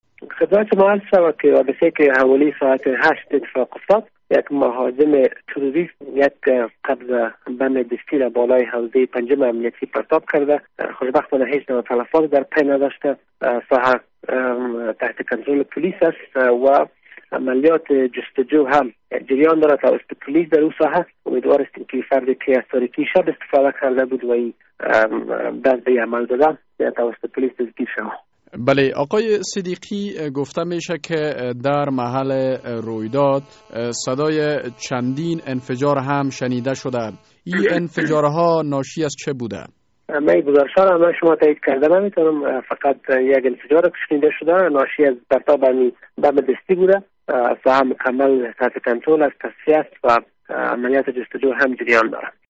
مصاحبه در مورد پرتاب بمب دستی بر حوزه 5 امنیتی در کابل